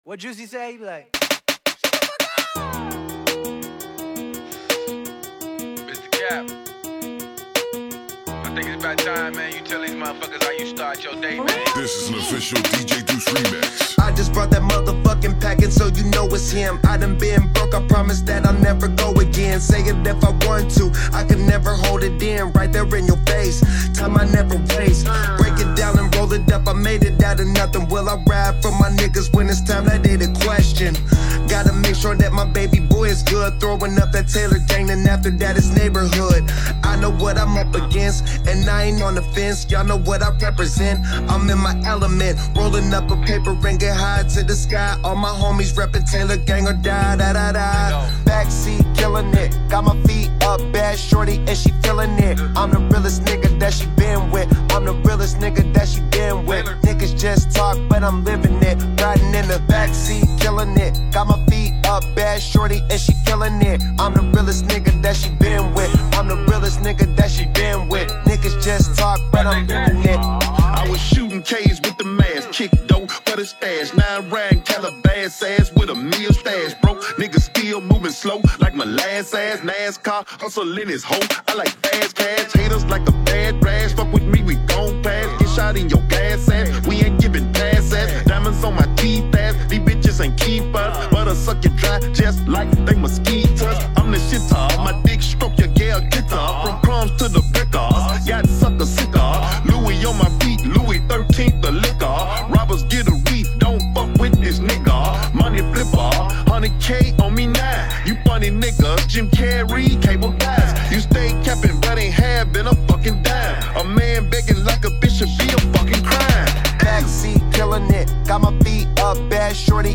remixed version